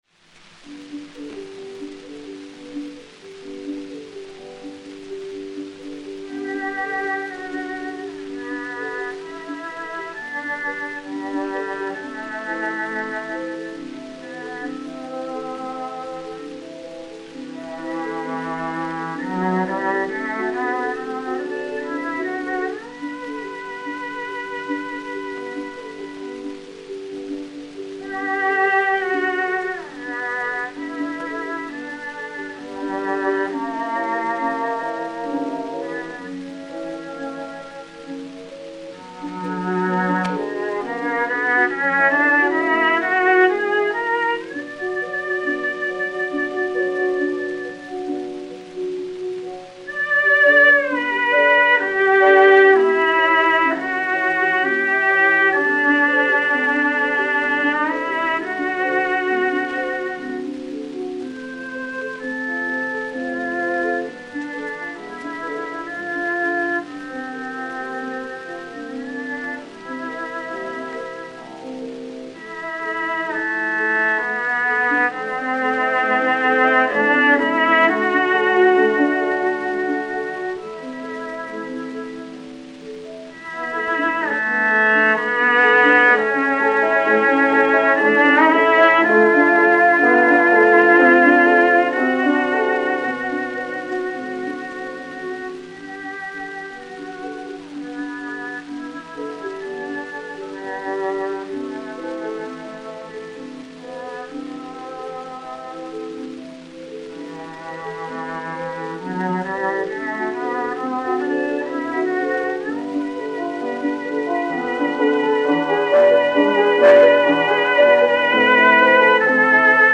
violoncelle
piano
Gramophone DA 776, mat. 3-7870, enr. à Camden le 31 janvier 1928